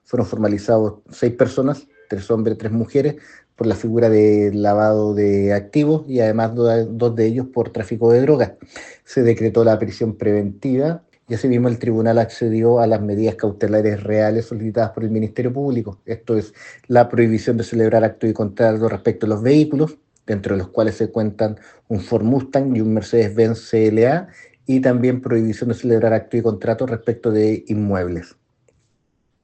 El fiscal de Lautaro, Miguel Ángel Velásquez, entregó detalles sobre la resolución del tribunal, precisando que son 3 hombres y 3 mujeres los imputados, dos de los seis por tráfico de drogas.